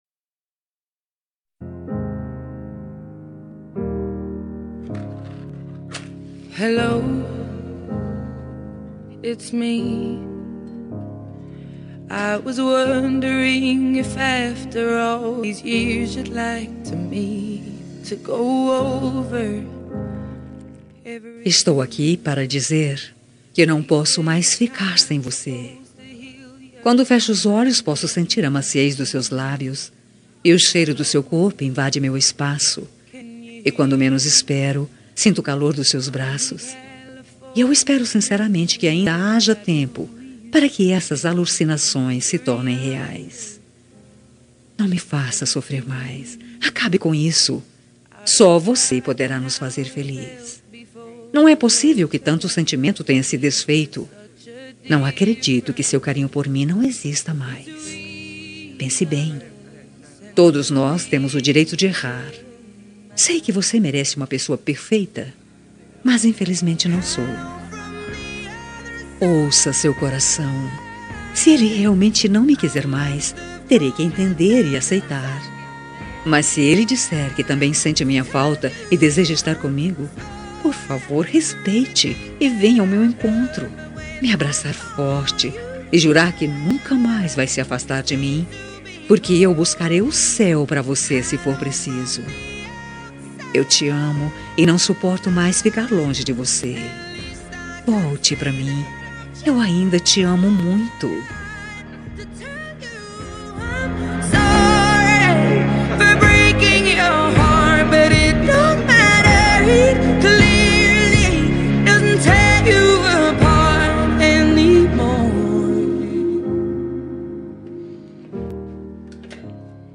Telemensagem de Reconciliação Romântica – Voz Feminina – Cód: 970